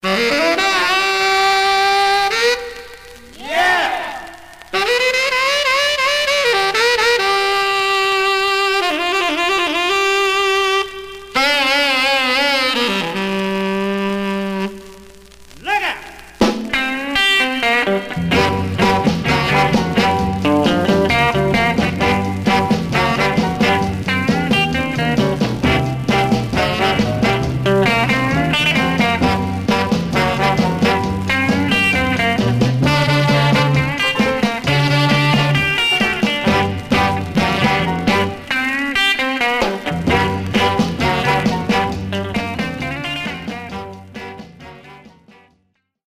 Some surface noise/wear
Mono
R & R Instrumental